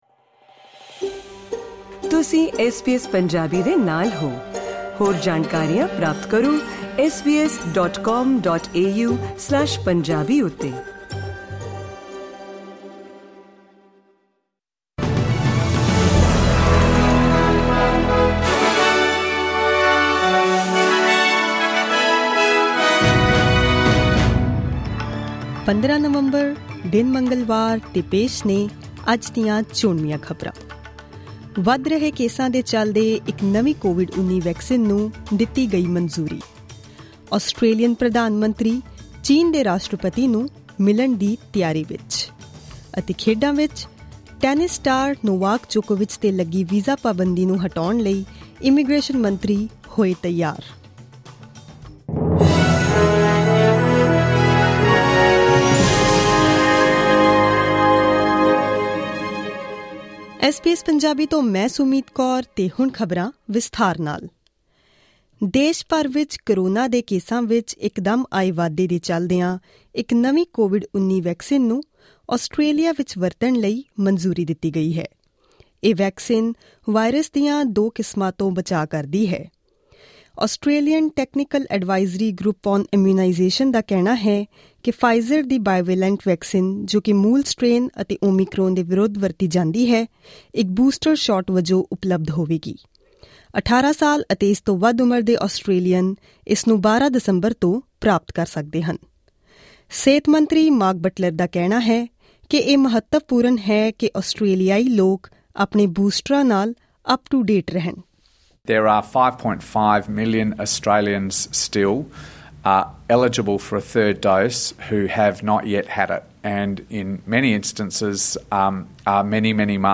Click on the player at the top of the page to listen to this news bulletin in Punjabi.